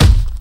Kick6.wav